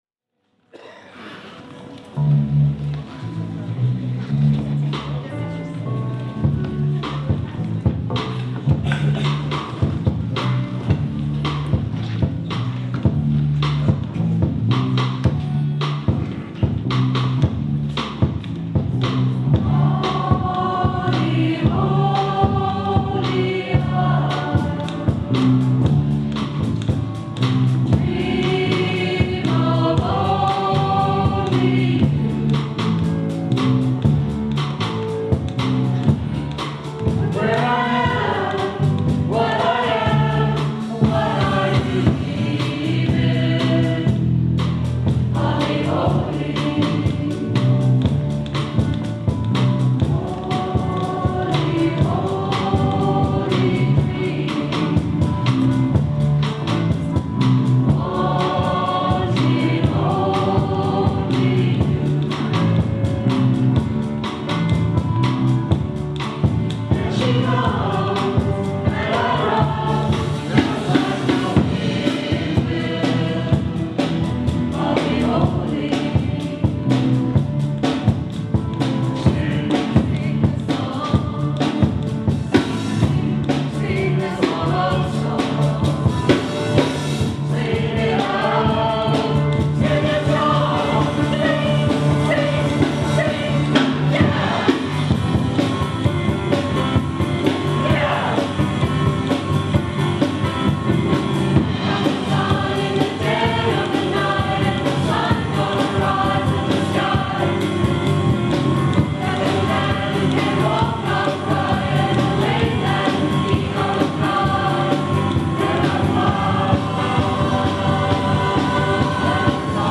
Reunion 2005 - Audio
Concert - Saturday July 2 at CCHS
These were not mic'd, so there is a fair bit of ambient noise, and the overall volume level is quite low, but hey! these are the best we've got, and I love'em.